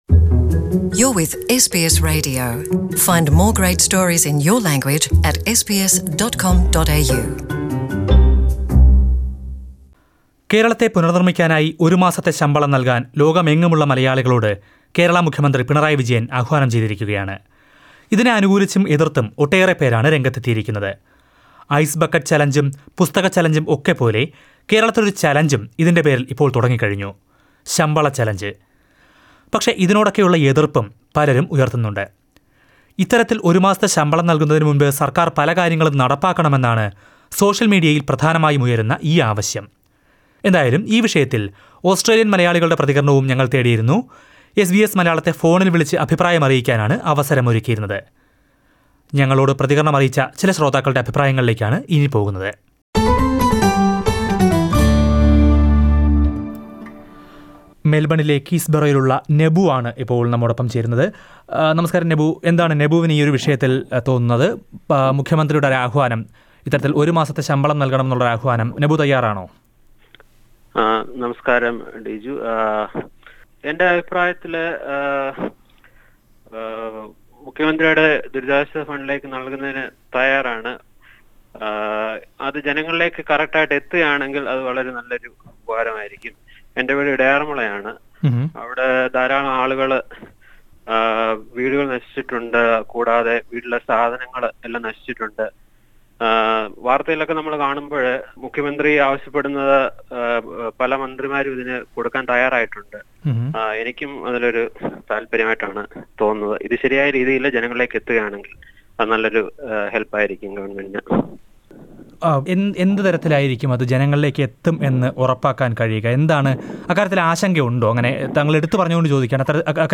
SBS Malayalam asked this to Australian Malayalees and here are the answers..